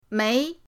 mei2.mp3